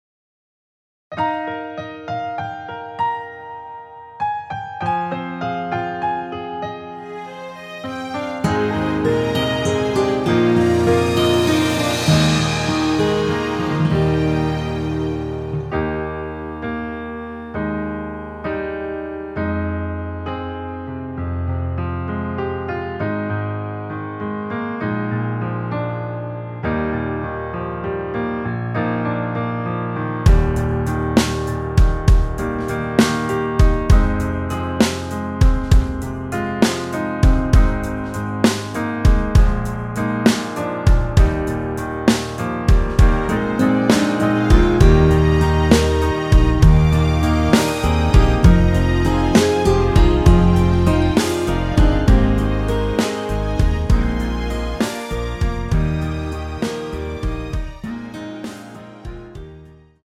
원키에서(-5)내린 짧은 편곡 MR입니다.
Eb
앞부분30초, 뒷부분30초씩 편집해서 올려 드리고 있습니다.
중간에 음이 끈어지고 다시 나오는 이유는